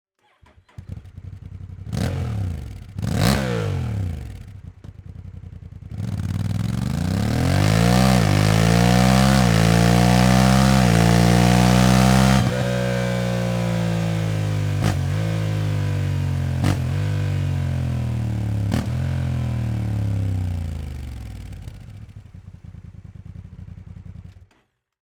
Die Abgasanlage liefert einen tiefen und kraftvollen Sound aus dem Parallel-Twin-Motor.